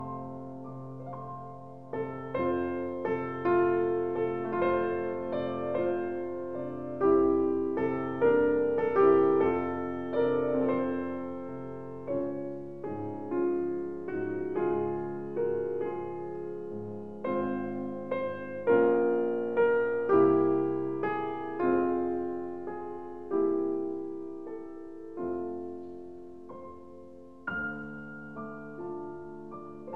Жанр: Классика
Classical, Piano